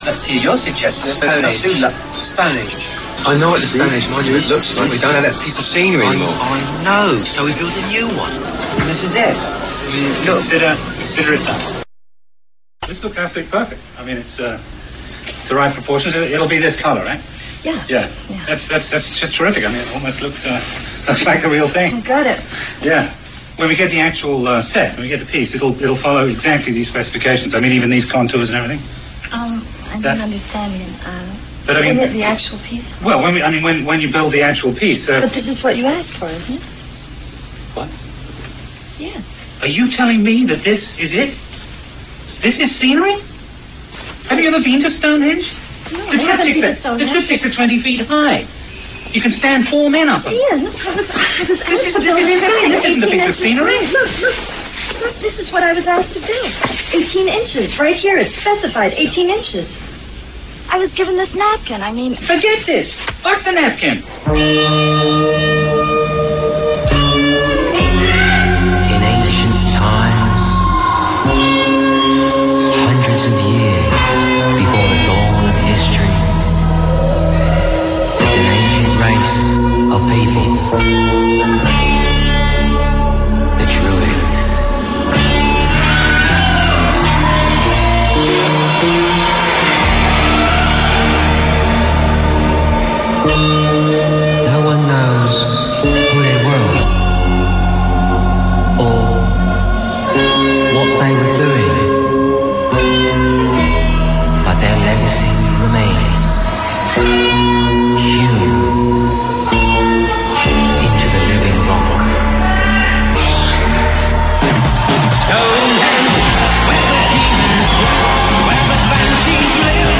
Audio clip contains strong language which may offend